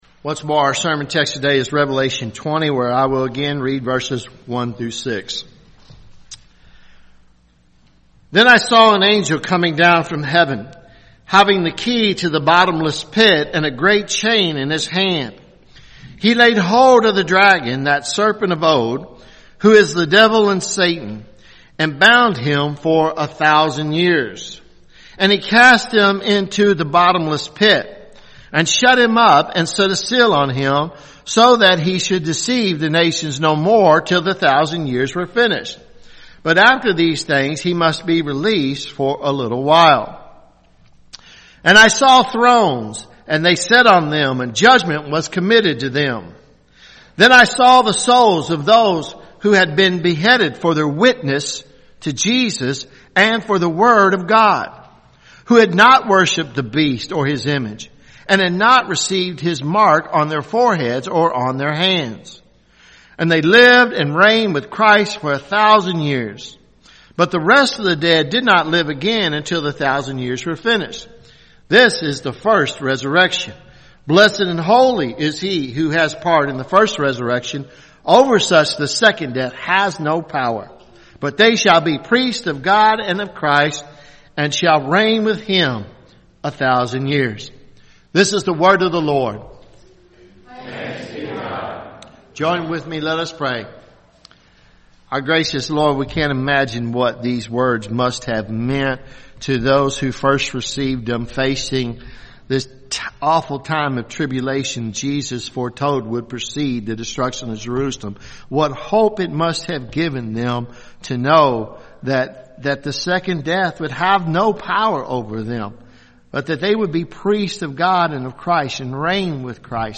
Revelation sermon series , Sermons